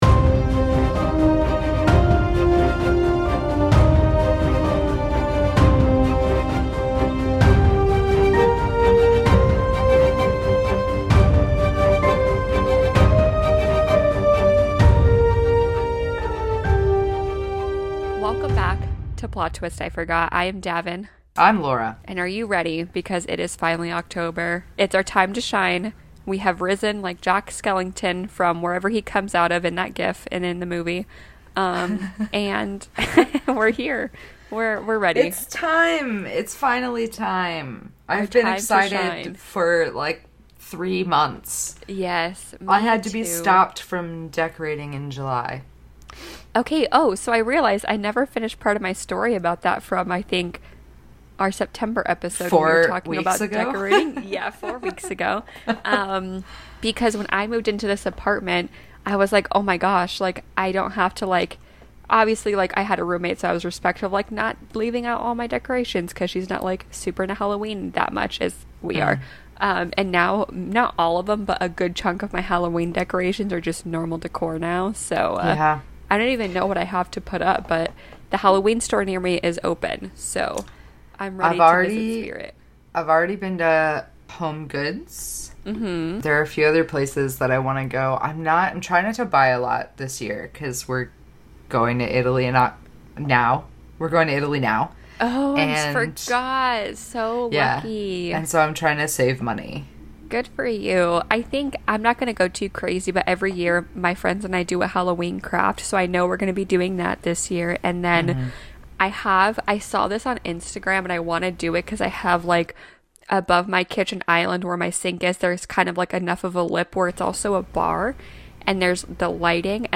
Today, we’re talking all things horror. Halloween decorations, our October tbrs, a big scare, and some giggling like teenagers.